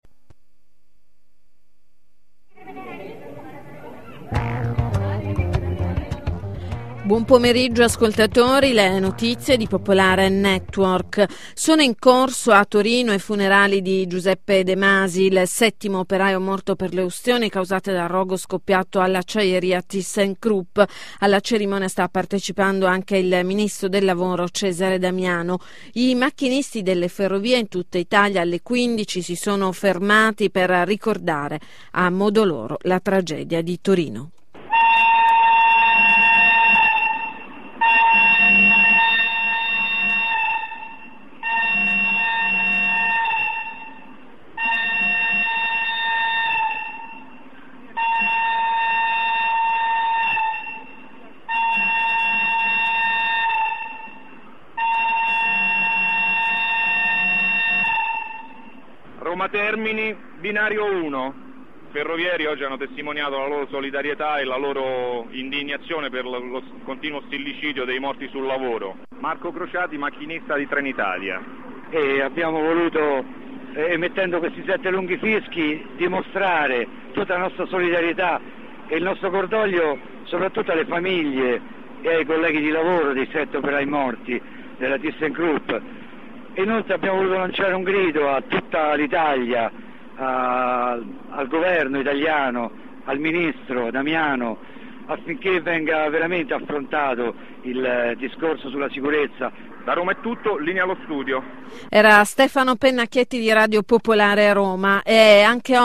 Dell'iniziativa promossa dall'Assemblea Nazionale dei Ferrovieri (sette fischi emessi dai treni in concomitanza con l'inizio dei funerali della settima vittima della Thyssen) ne ha dato notizia Radio Popolare al Radio Giornale delle 15,30.